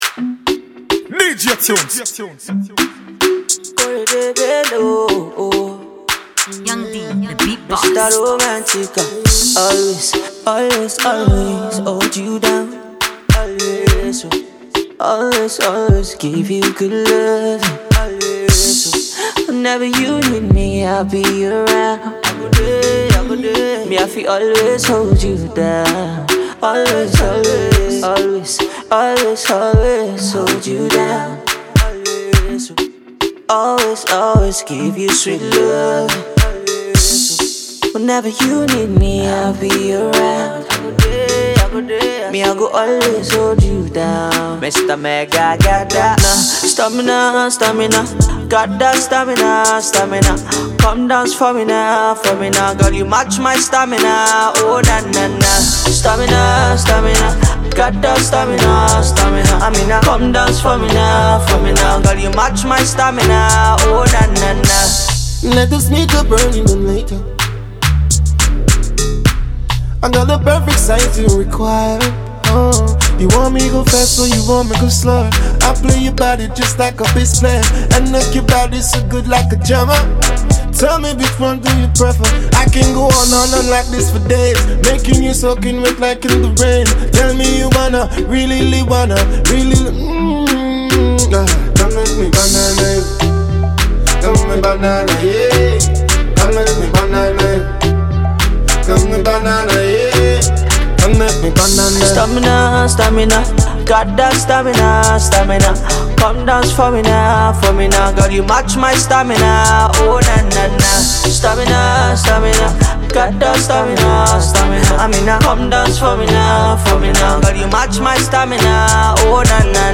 International Afrobeats record